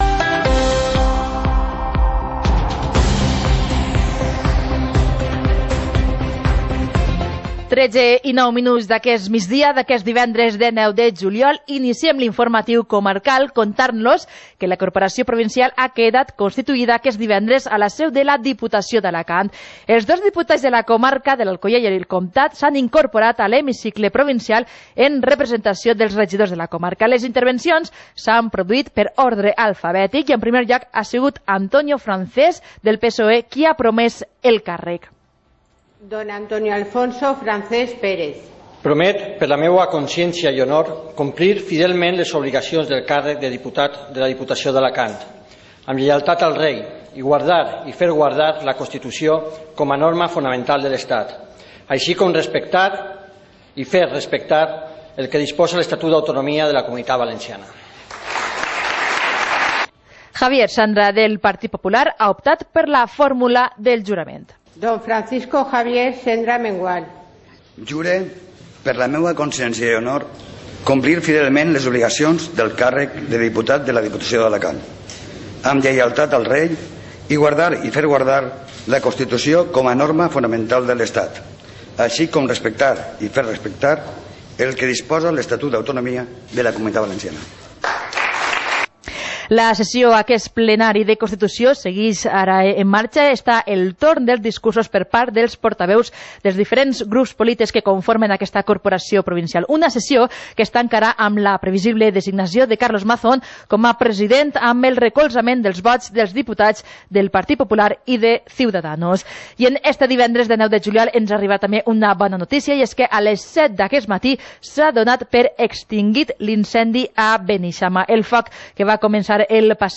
Informativo comarcal - viernes, 19 de julio de 2019